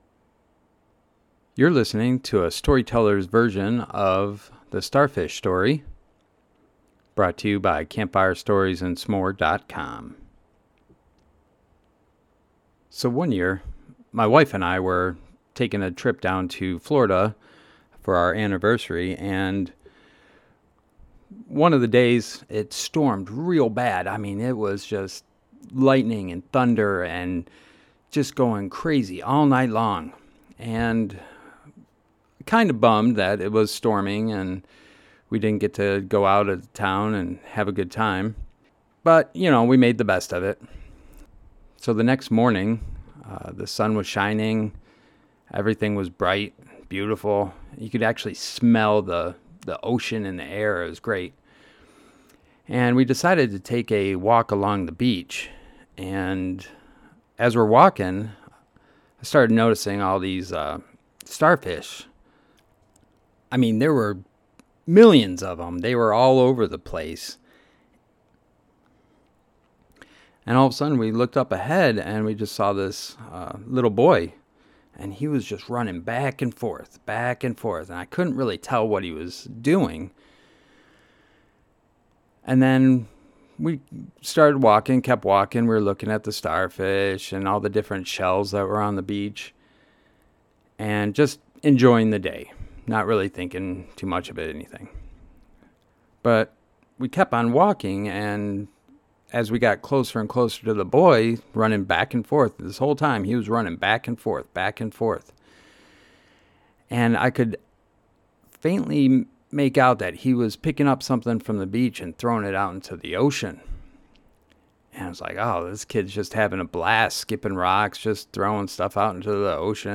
This is a Story Tellers Version of the story above.
The-Starfish-Story-Story-Teller-Version.mp3